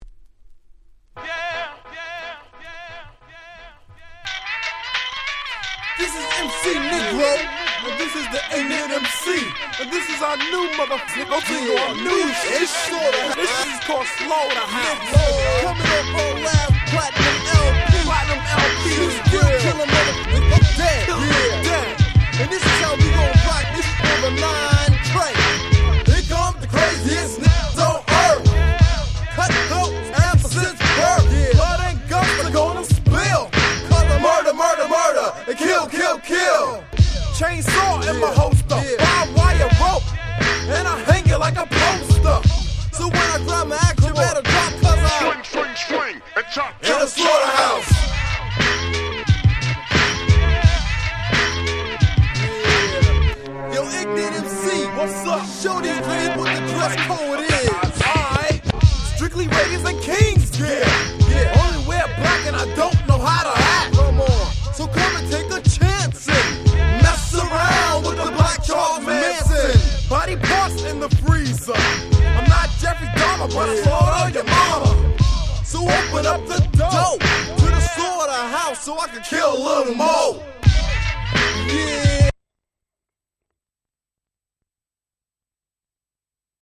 94' Super Nice Hip Hop !!